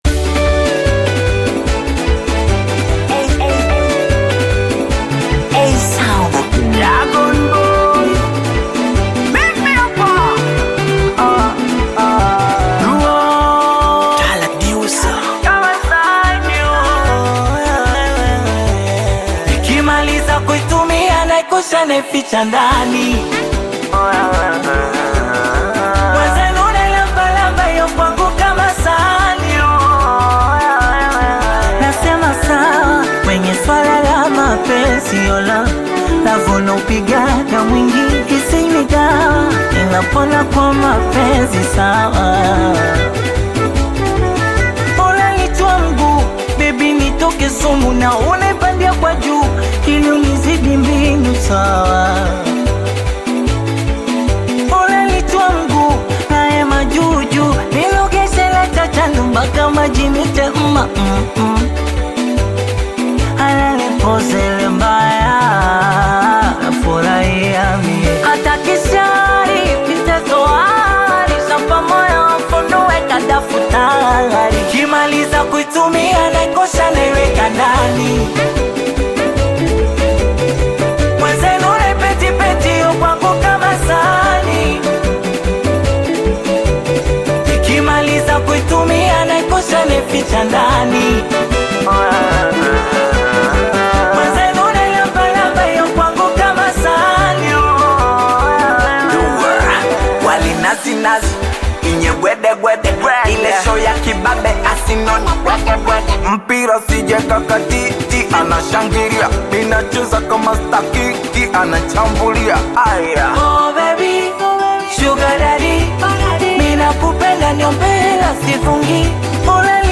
Tanzanian Bongo Flava singeli
high-energy and dance-worthy anthem